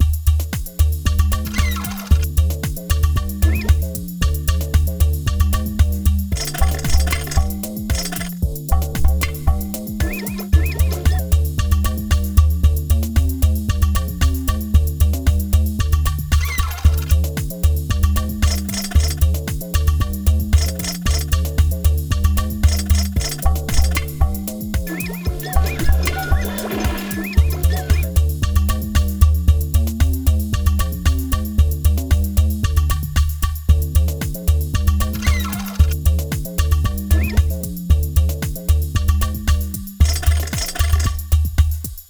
Tecno selva (bucle)
tecno
melodía
repetitivo
ritmo
sintetizador
Sonidos: Música